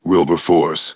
synthetic-wakewords
ovos-tts-plugin-deepponies_Kratos_en.wav